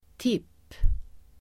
Uttal: [tip:]